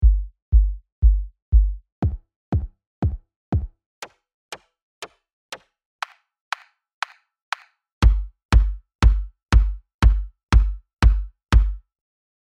Vereinfacht gesagt, das sind halt mehrere Bassdrums!
Ein schnelles Beispiel wie sich solche layer anhören hier: lg..